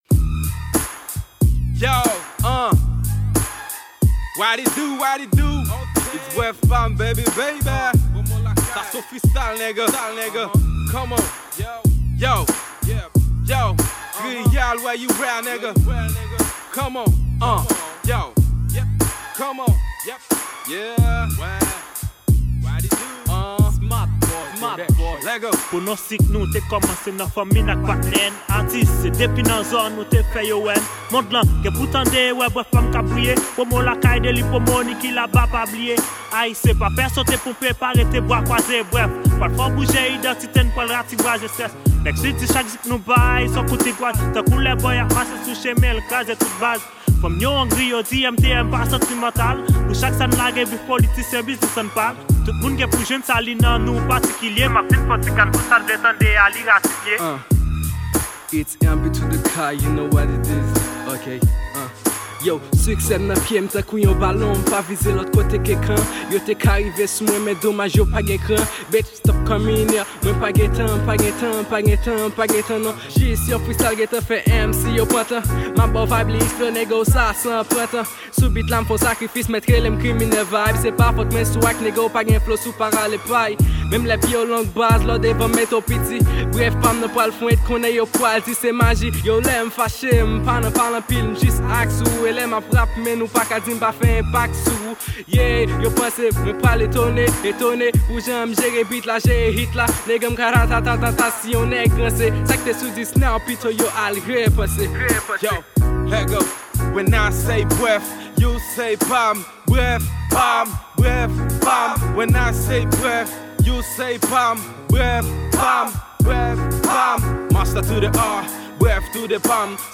Genre: Rap-Freestyle.